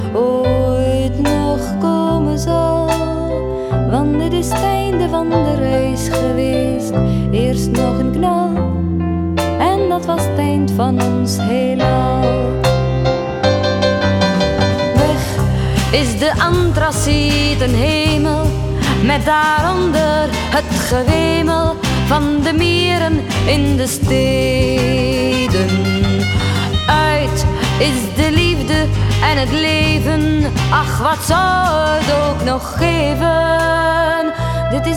Жанр: Поп музыка
Pop